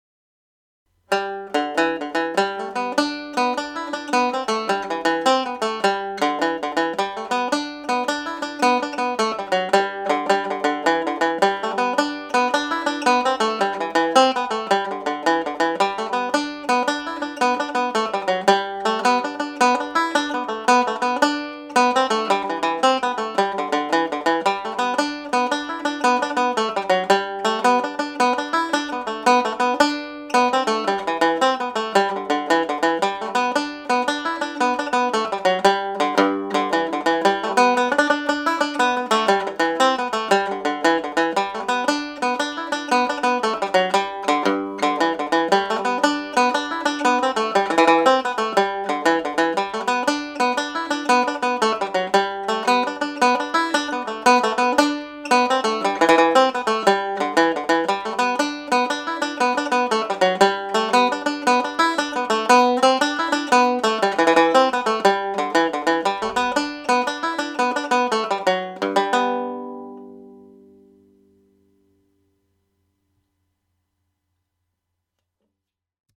complete tune played at jig speed